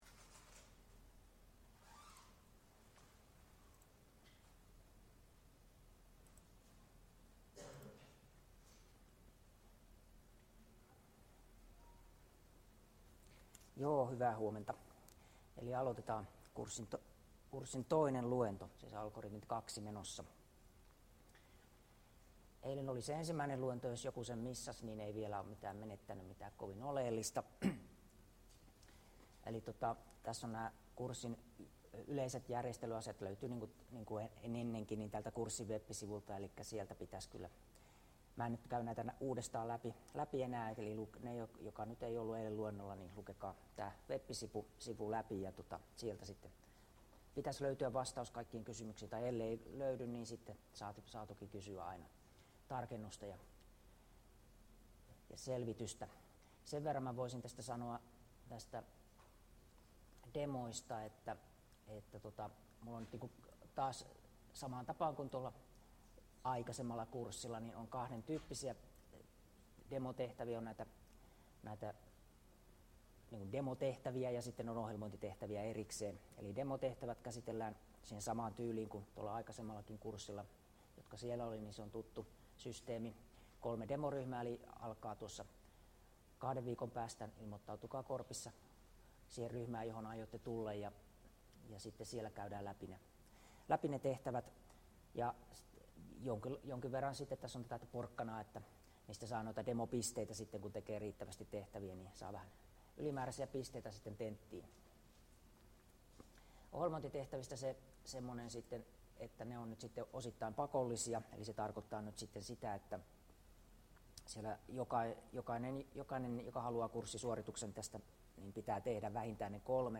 Luento 2 — Moniviestin